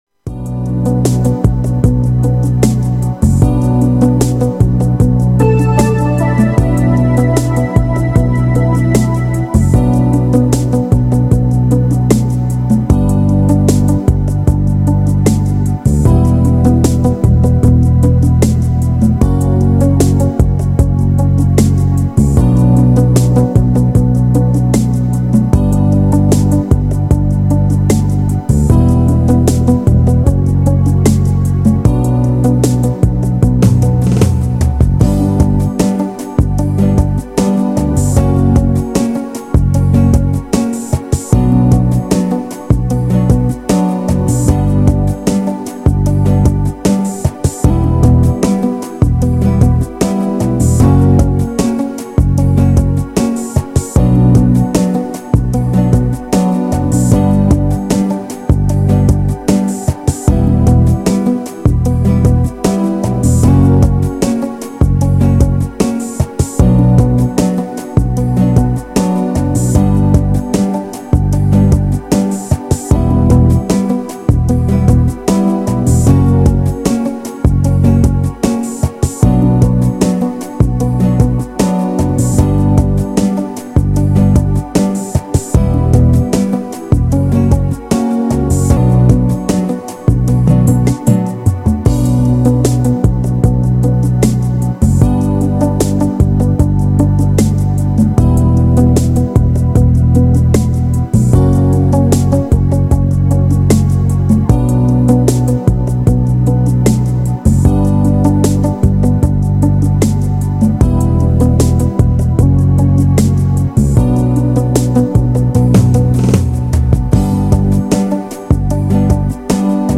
Детские песни В закладки 😡 Замечание!